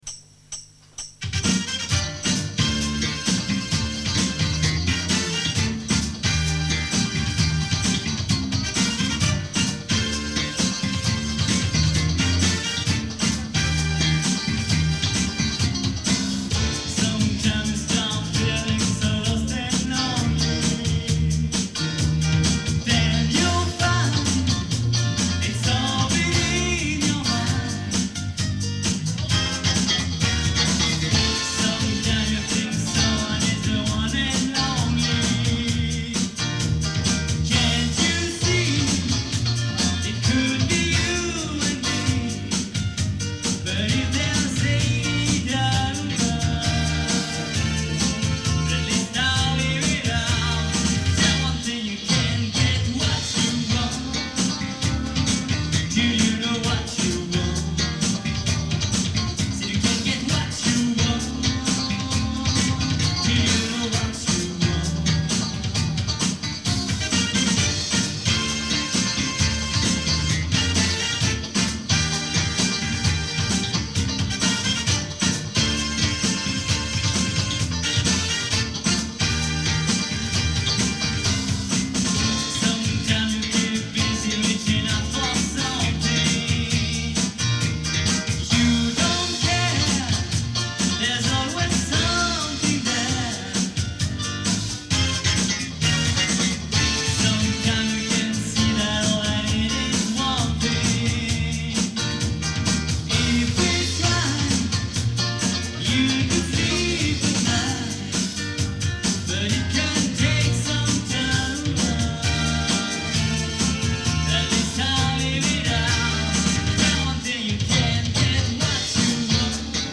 LIVE EN PUBLIC (1983 - 2020) - Un résumé...
Via Brazil (St Laurent du Var) 14 Mars 1991